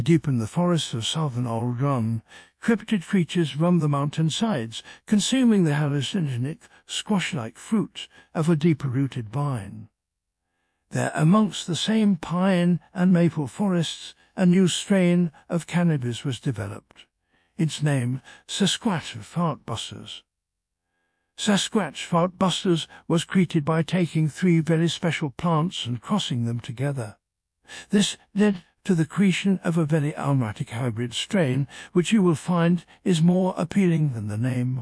sir-david-attenborough-Deep_in_the_forests_.wav